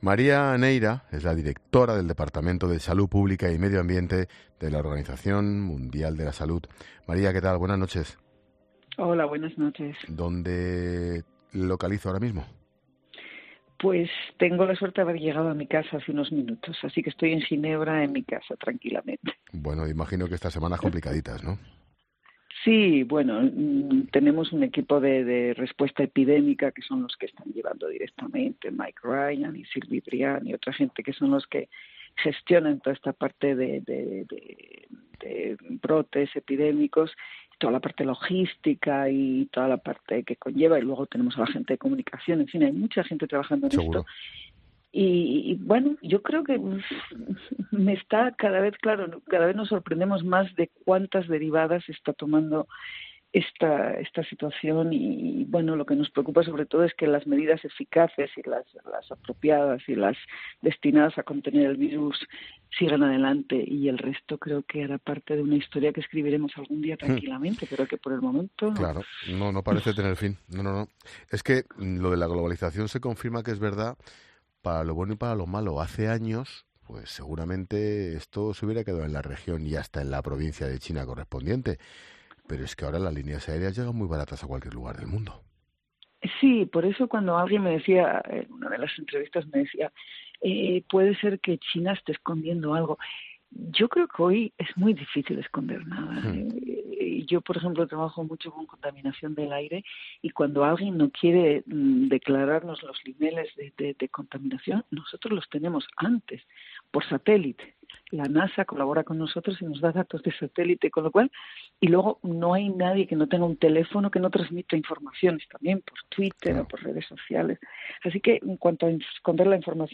La globalización, en parte, provoca que también se propague esta enfermedad... tal y como reflexionaba e indicaba el director de 'La Linterna' durante esta entrevista, Ángel Expósito.